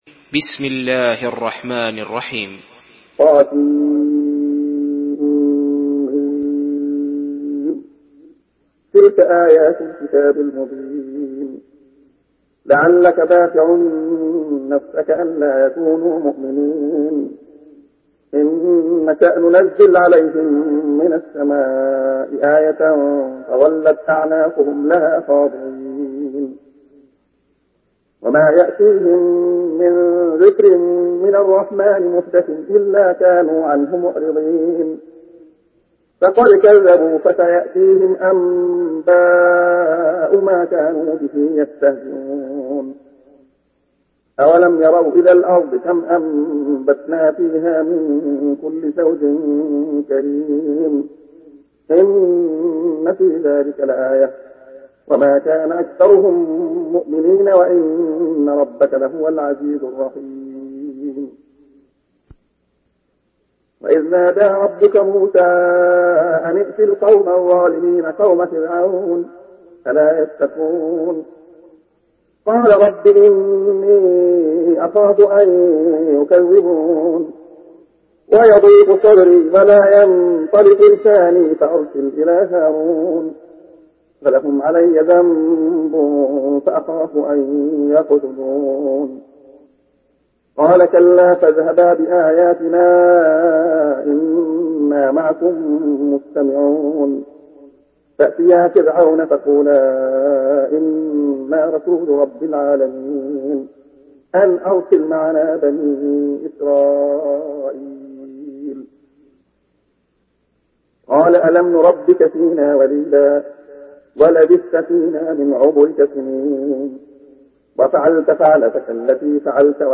سورة الشعراء MP3 بصوت عبد الله خياط برواية حفص
مرتل حفص عن عاصم